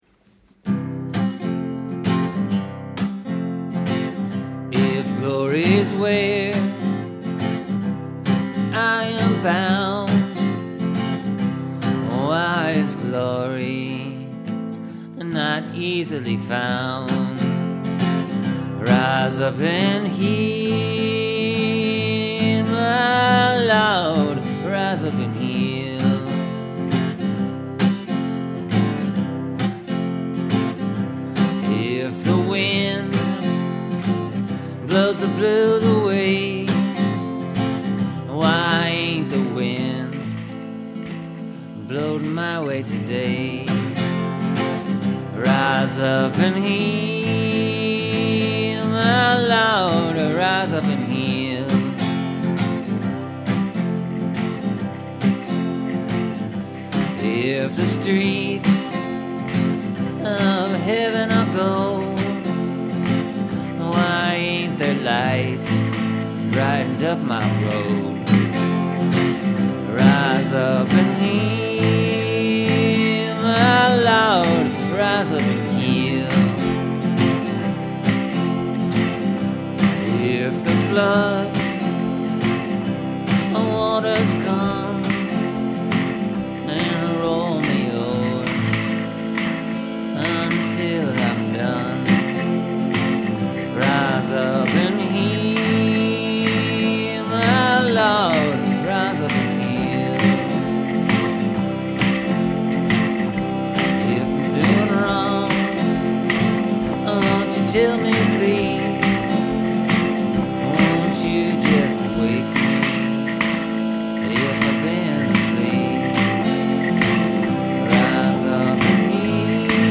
Here’s the re-record for this week. I used the 60’s Frontalini Power Organ again.